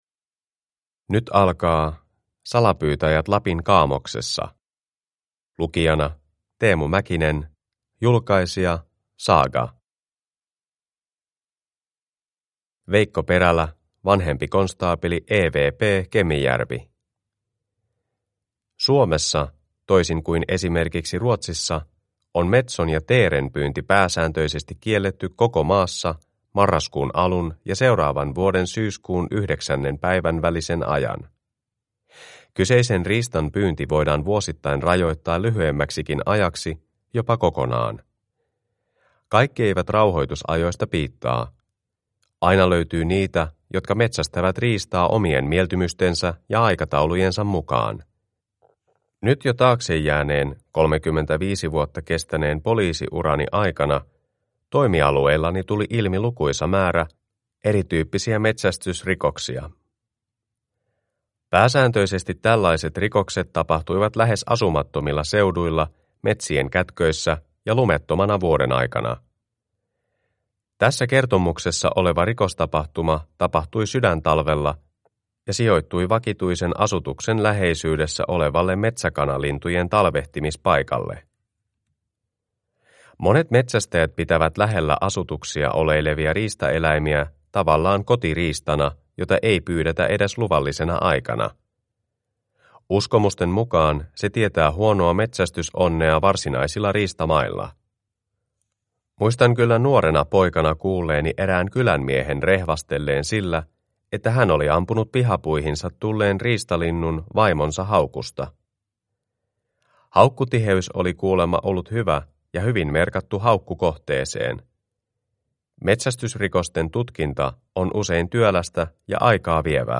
Salapyytäjät Lapin kaamoksessa (ljudbok) av Eri tekijöitä